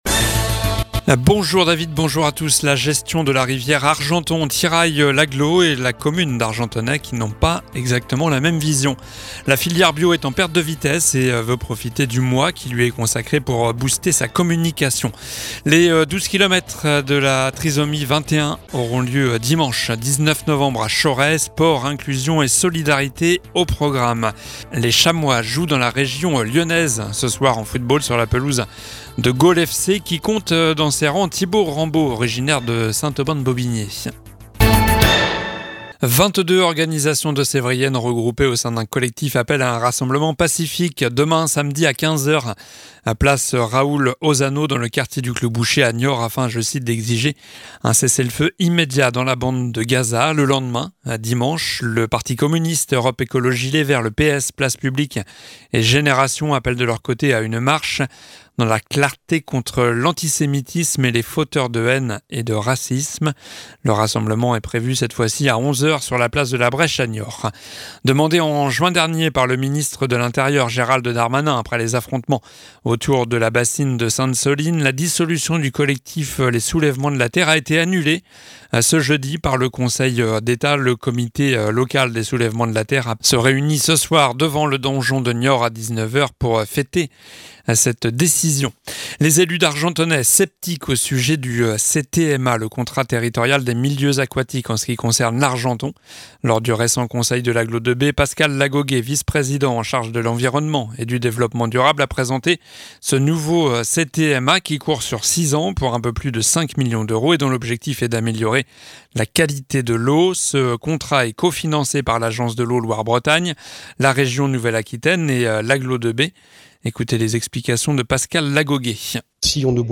Journal du vendredi 10 novembre (midi)